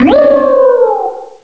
pokeemerald / sound / direct_sound_samples / cries / alomomola.aif
alomomola.aif